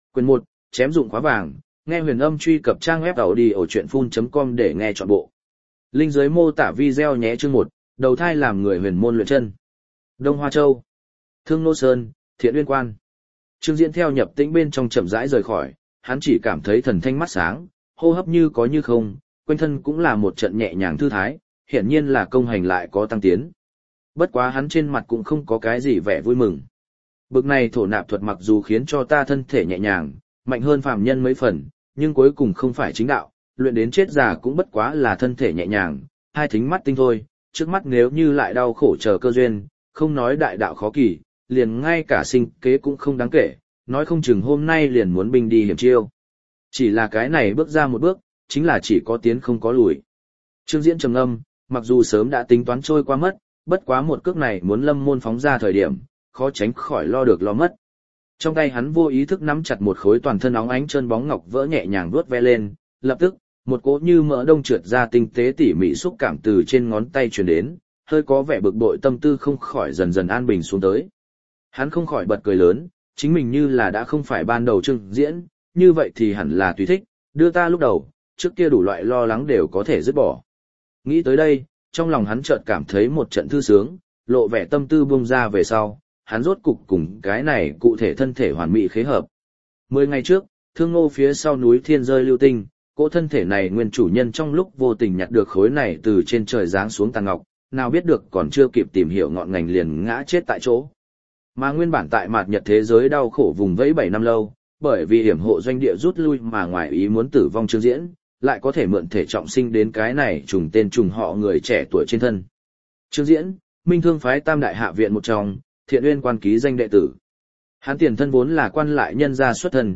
Đại Đạo Tranh Phong Audio - Nghe đọc Truyện Audio Online Hay Trên TH AUDIO TRUYỆN FULL